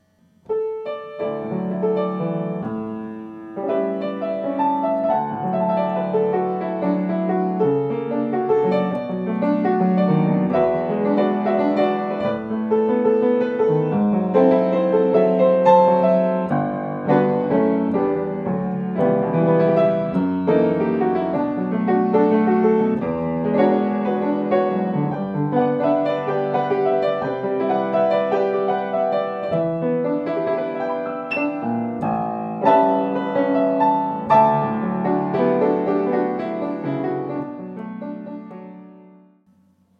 from 2016 recital in Florence, Italy.